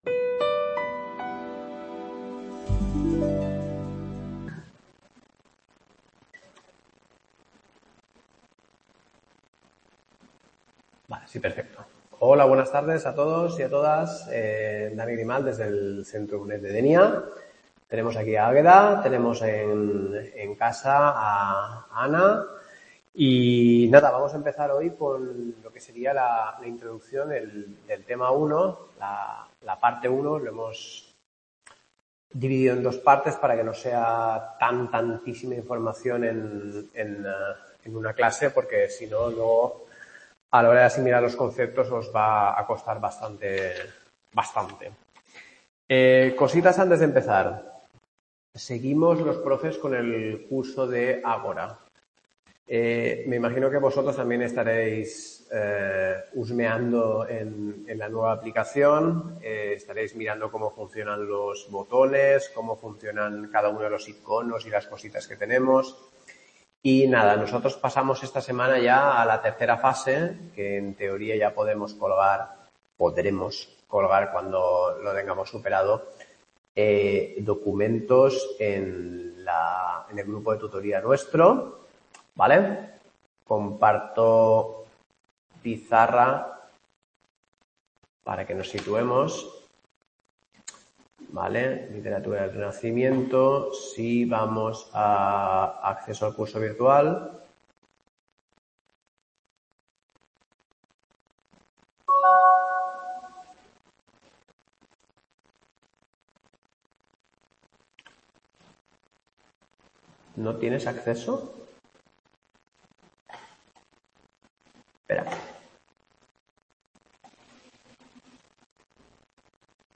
Clase 2, literatura del Renacimiento.